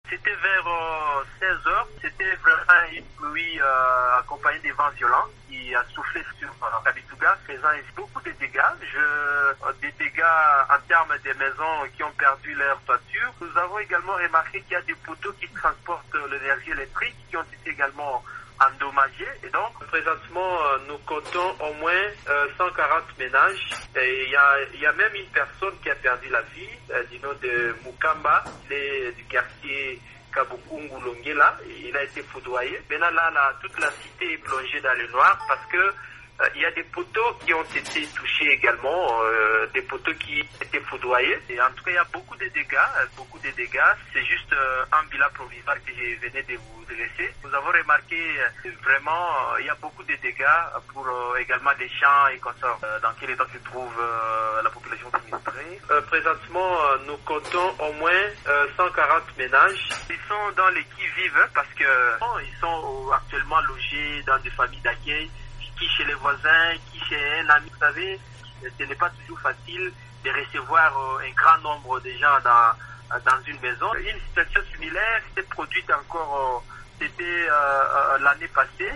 Notre correspondant dans la région a recueilli le témoignage d'un habitant de Kamituga.